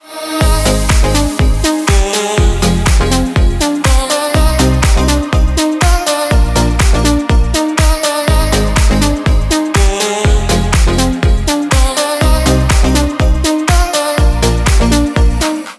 громкие
dance
Electronic
Стиль: deep house